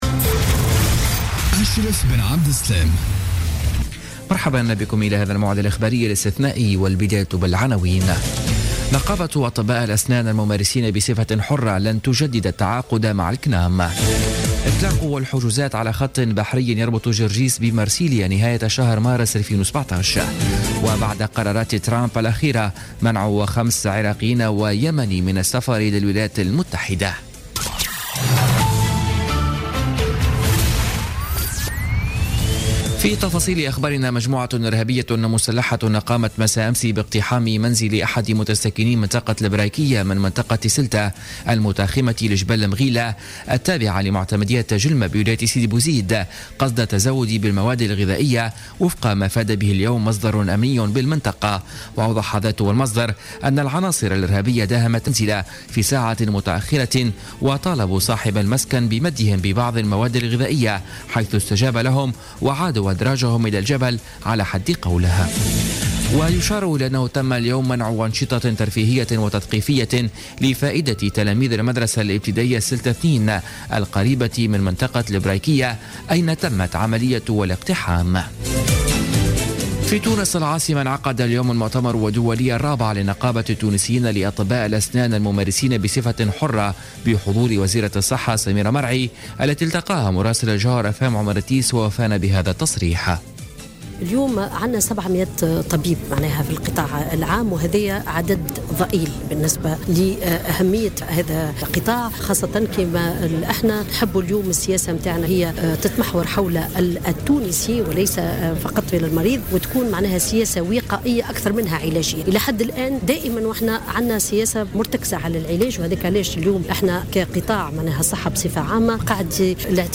نشرة أخبار السابعة مساء ليوم السبت 28 جانفي 2017